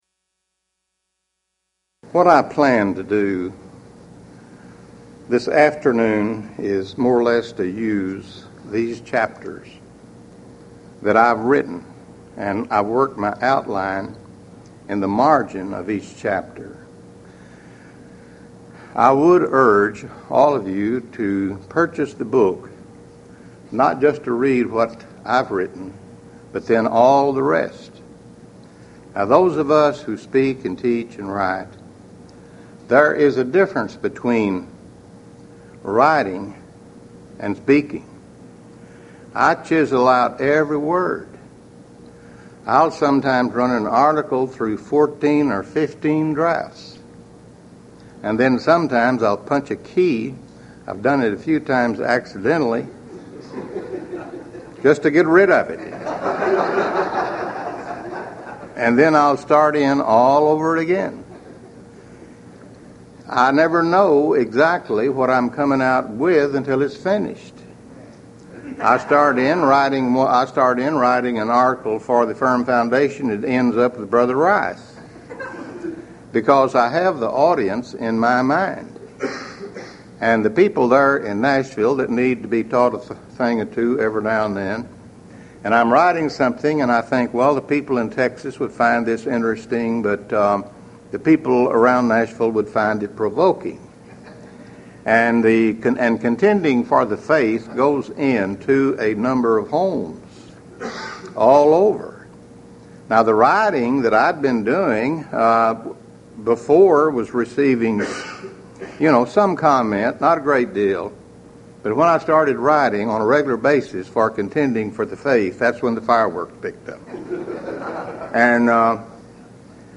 Series: Houston College of the Bible Lectures Event: 1996 HCB Lectures